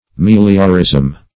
Meliorism \Mel"io*rism\, n.